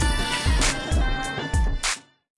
Media:Urban_Trader_001.wav 部署音效 dep 局内选择该超级单位的音效